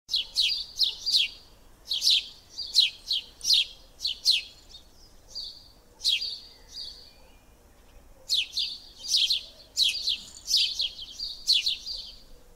Zdaj si lahko pogledaš odlomek filma, v katerem si te drobne ptice slišal.